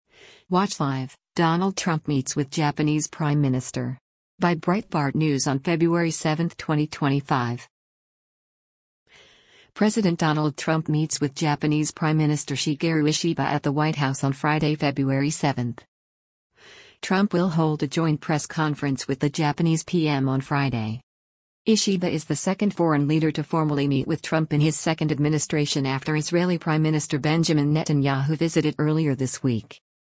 President Donald Trump meets with Japanese Prime Minister Shigeru Ishiba at the White House on Friday, February 7.
Trump will hold a joint press conference with the Japanese PM on Friday.